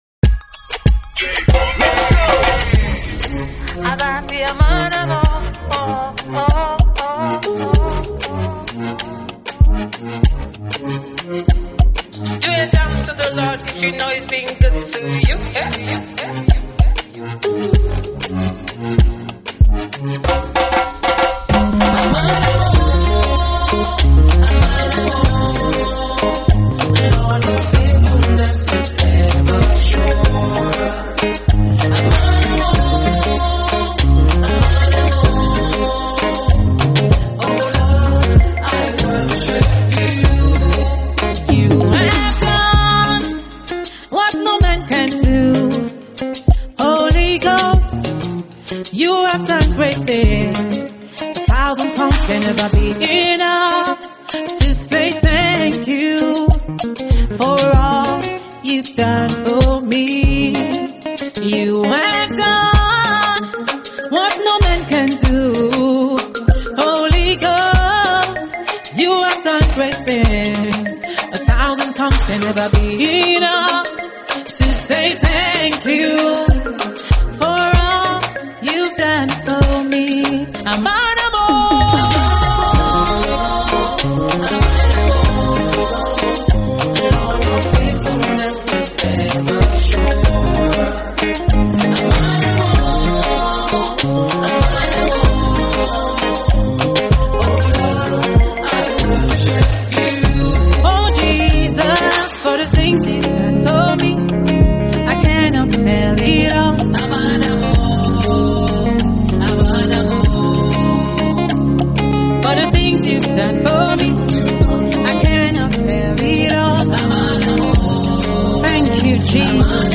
Gospel Music
praise song